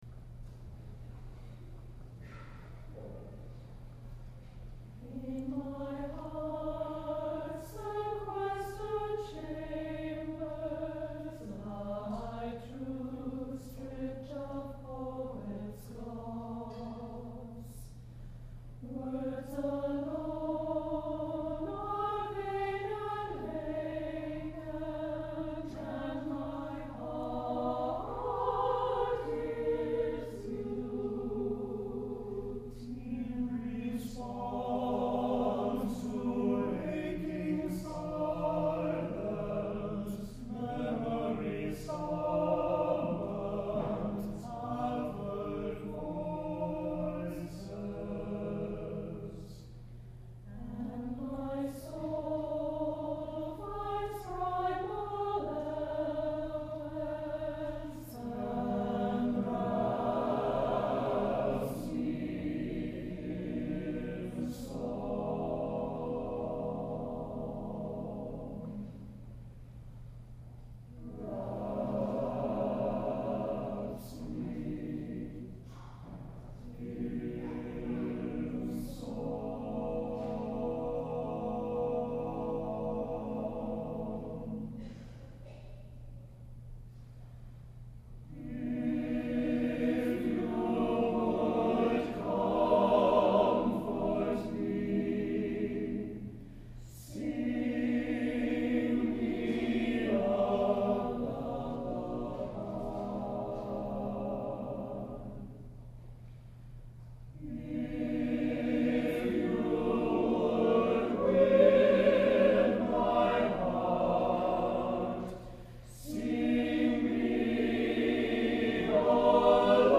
Clips from the Cathedral
Beautiful anthem by Daniel E. Gawthrop, performed by the Trinity Cathedral Choir, Phoenix, Arizona